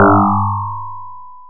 Le son ci-dessus est modulé en fréquence et la modulation évolue avec le temps. L'amplitude est également modulée (ondulation et décroissance générale).
boing.wav